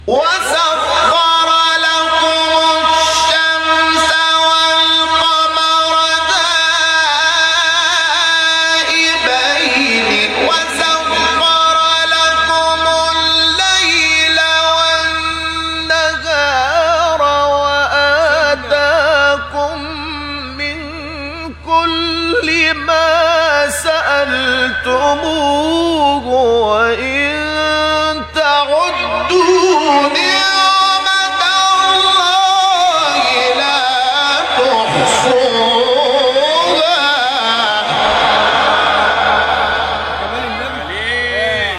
مقطع تلاوت جدید سوره ابراهیم استاد محمود شحات | نغمات قرآن
سوره : ابراهیم آیه : 33-34 استاد : محمود شحات مقام : بیات قبلی بعدی